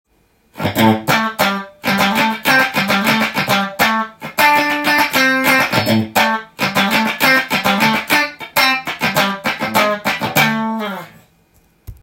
まずはシングルコイルでカッティング。
シングルコイルは細くジャキジャキした音が出ますし、